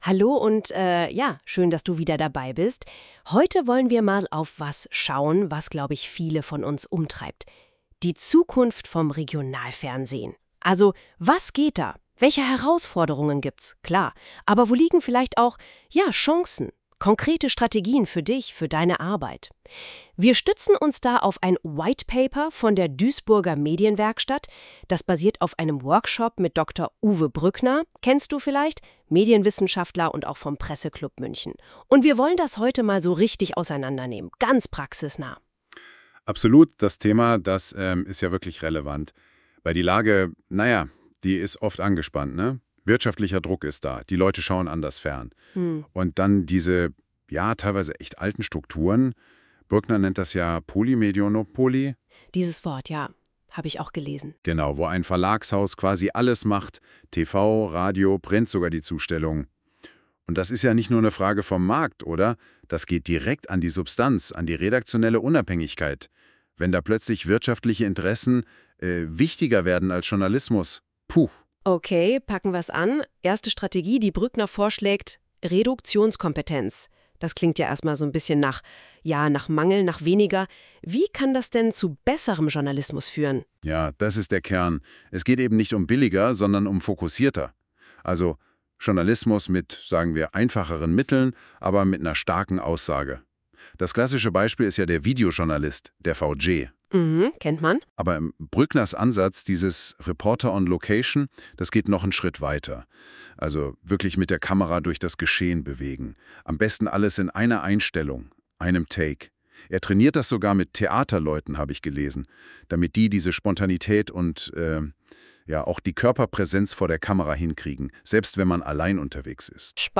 Unsere KI-Hosts sprechen über Reduktionskompetenz, journalistische Ethik, neue Vermarktungsansätze.